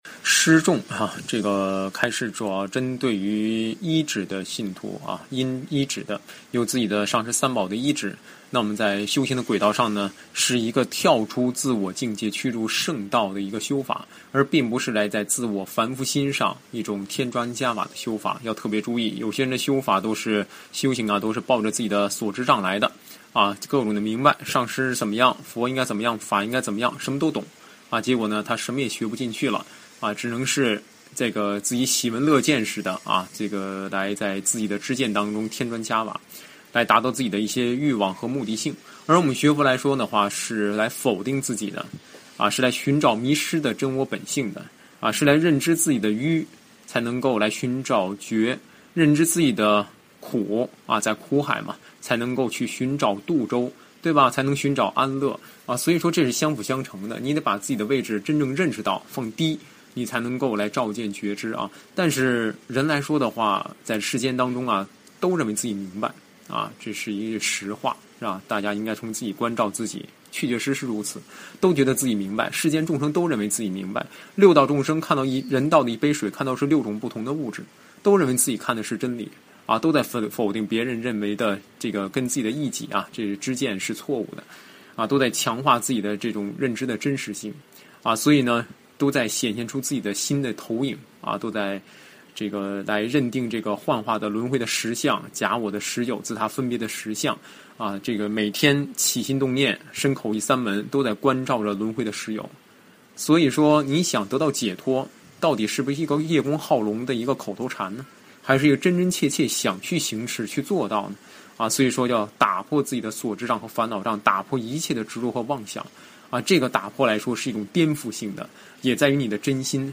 重点开示